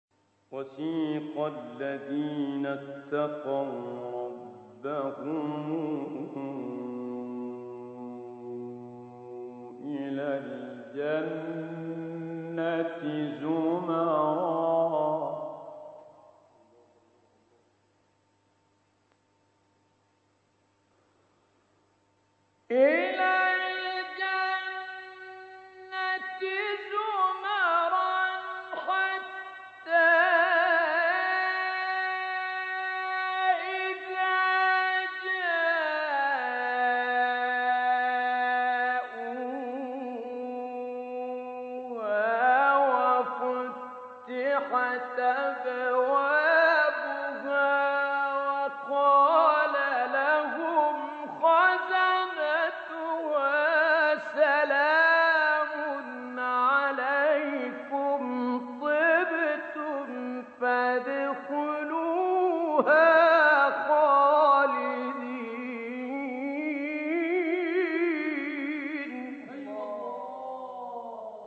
آیه 73 سوره زمر استاد متولی عبدالعال | نغمات قرآن | دانلود تلاوت قرآن